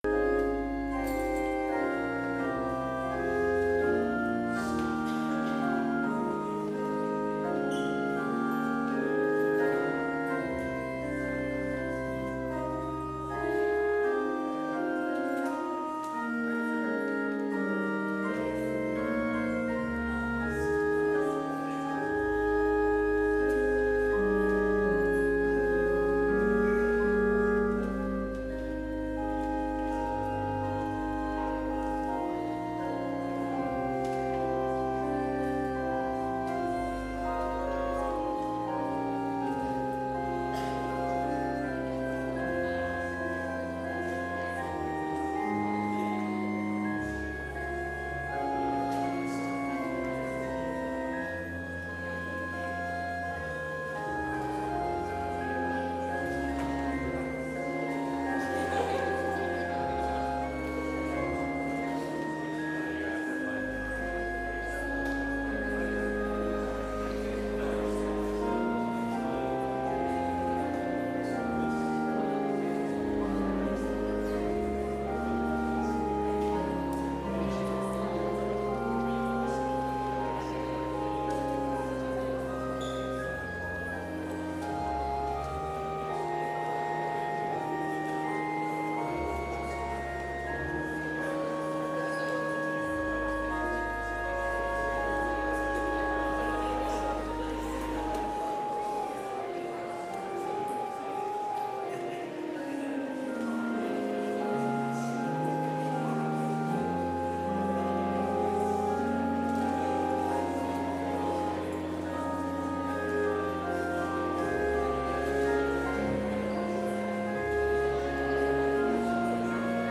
Complete service audio for Chapel - October 5, 2022